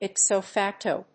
音節ip・so fac・to 発音記号・読み方
/ípsoʊfˈæktoʊ(米国英語), ípsəʊfˈæktəʊ(英国英語)/